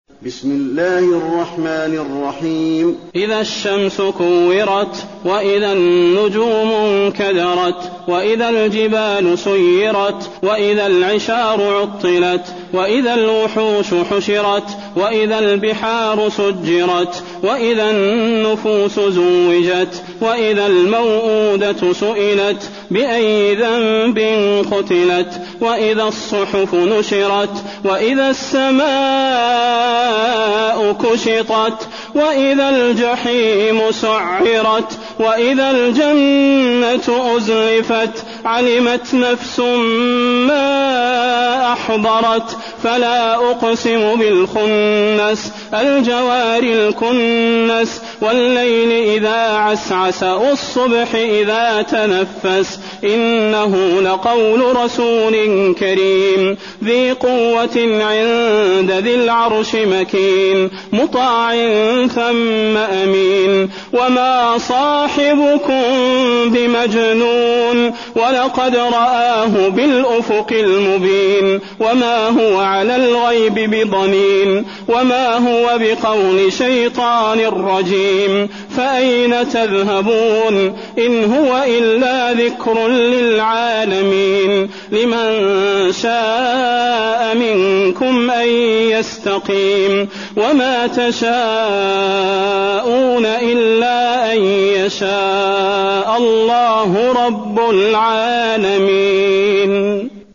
المكان: المسجد النبوي التكوير